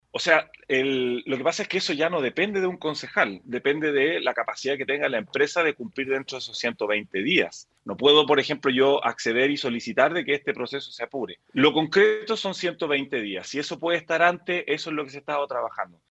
En conversación con la radio, el concejal por el Frente Amplio, Alejandro Aguilera, confirmó que de resultar todo bien, recién a finales de marzo la ciudad podría contar con el sistema de seguridad operativo.